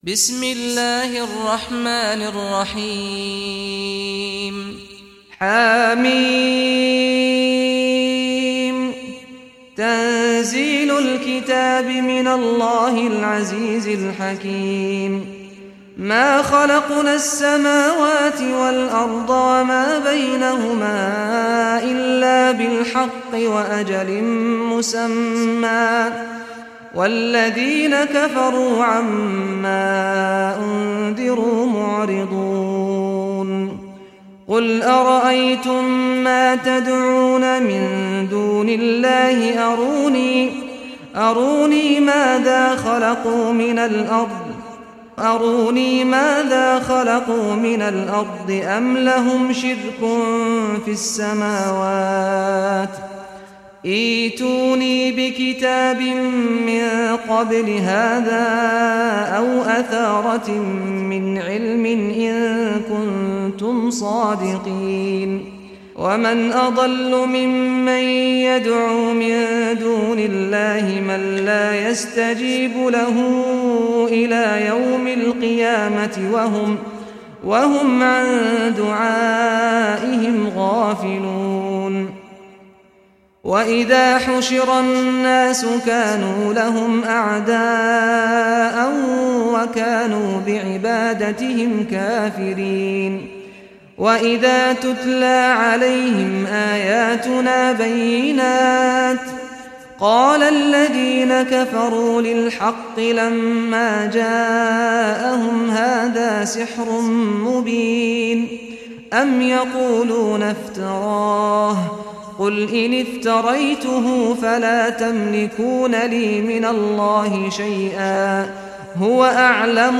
Surah Al-Ahqaf Recitation by Sheikh Saad al Ghamdi
Surah Al-Ahqaf, listen or play online mp3 tilawat / recitation in Arabic in the voice of Sheikh Saad al Ghamdi.
46-surah-al-ahqaf.mp3